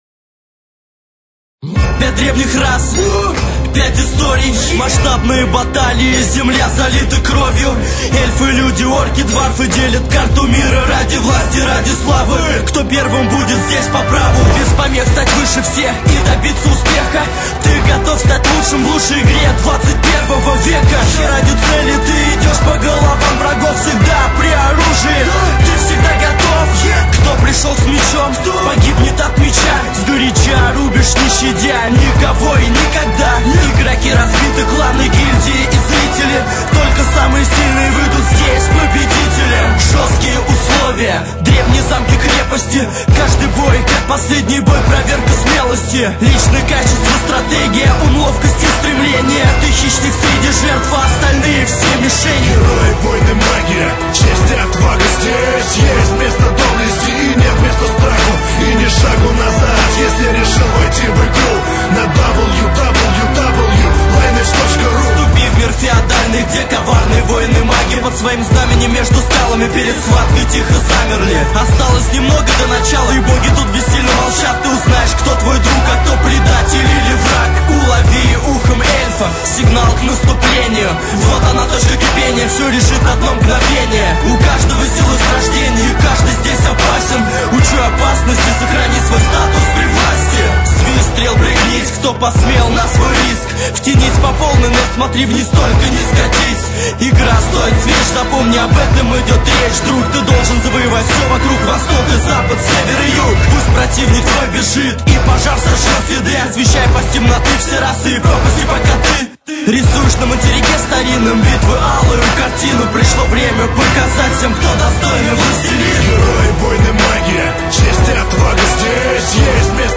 уж что я больше всего ненавижу так это русский рэп есть коешно пара норм песен о остальое такой трэш этот трэк не исключение брр мерзость